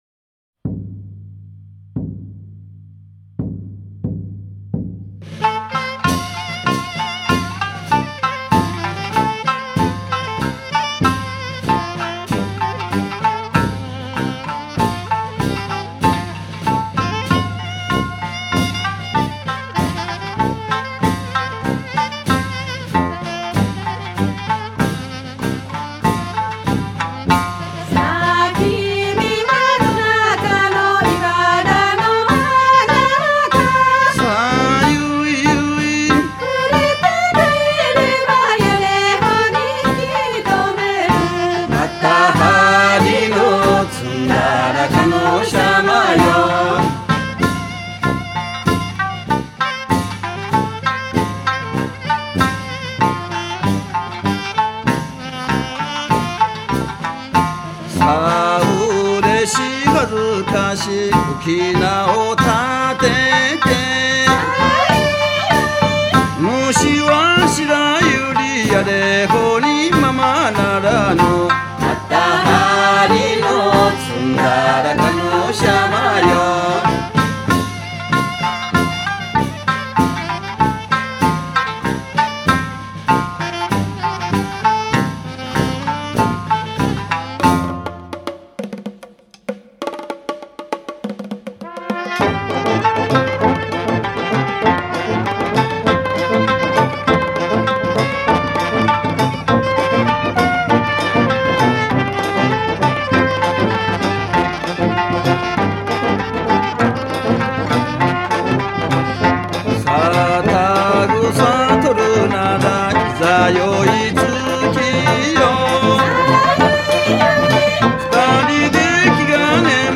そして、参加ミュージシャンは総勢３０人！